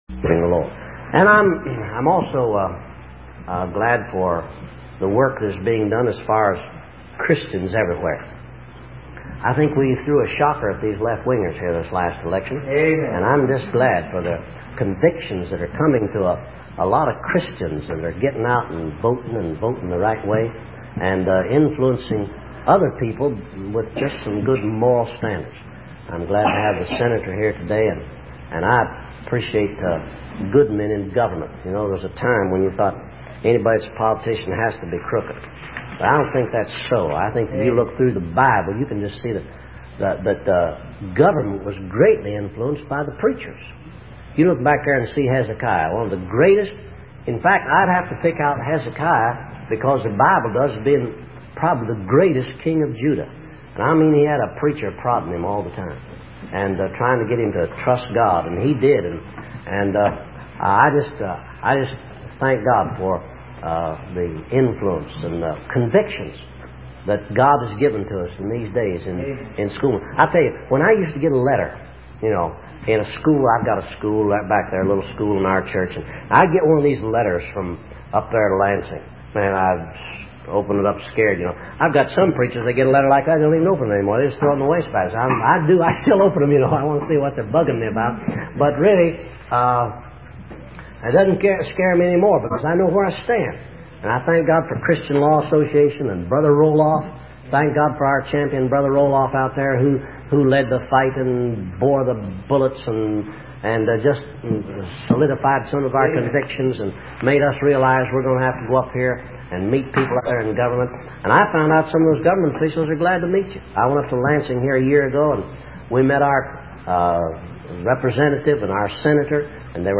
He came to our church in Port Orchard in 1993 and preached this message.